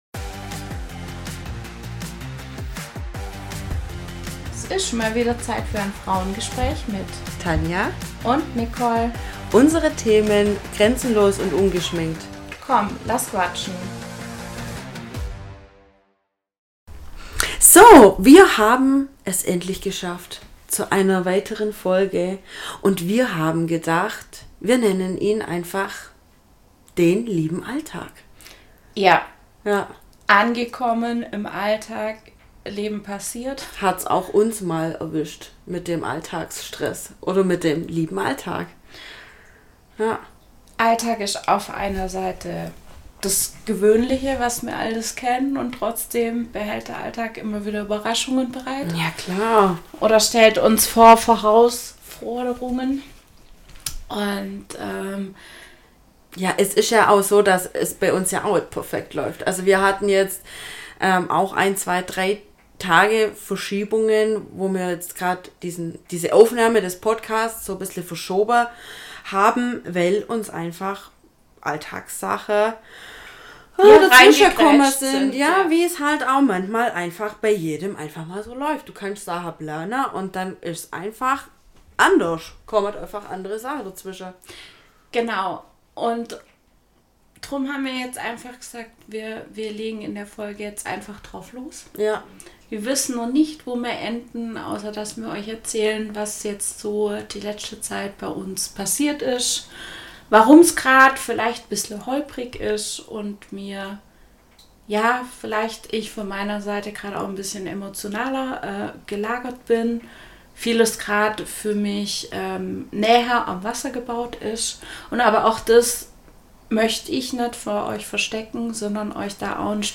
Wir wissen, dass unsere Tonqualität momentan nicht immer ganz zu 1000% passt.